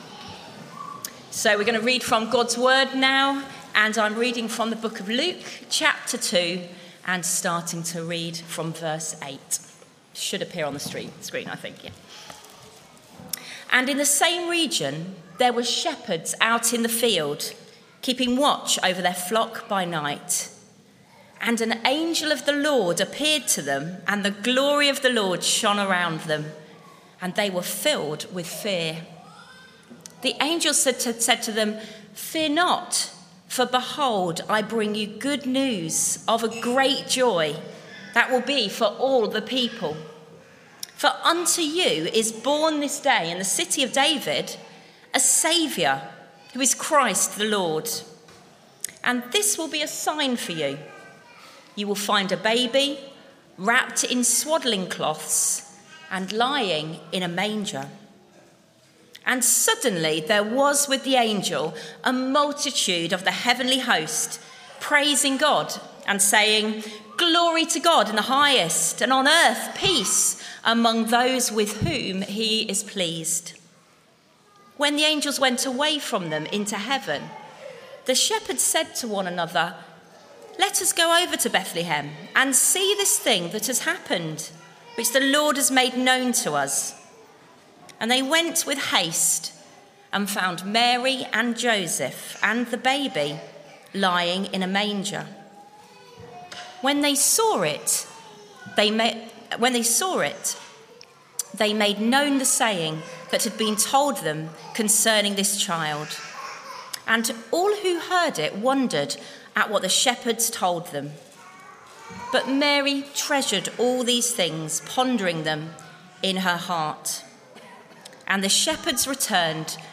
Media for Children's Carol Service on Tue 24th Dec 2024 14:30 Speaker
Theme: Why did Jesus come? Sermon - Audio Only Search media library...